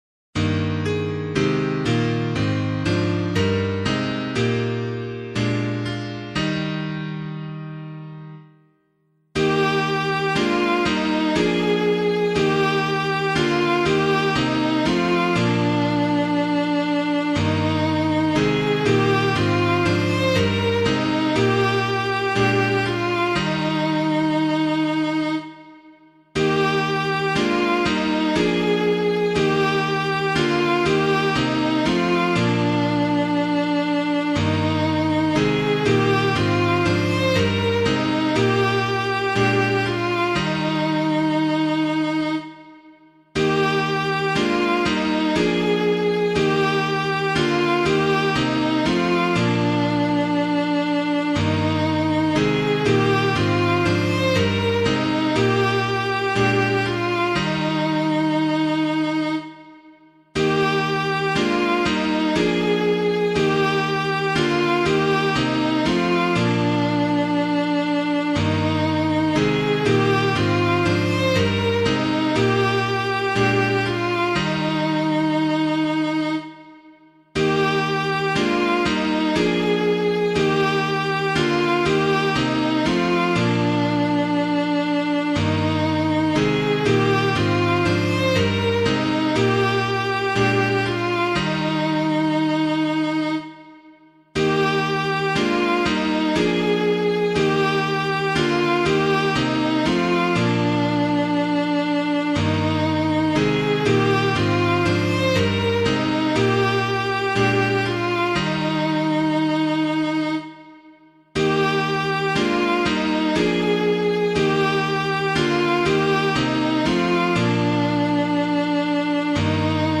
piano
Draw Nigh and Take the Body of the Lord [Neale - COENA DOMINI] - piano.mp3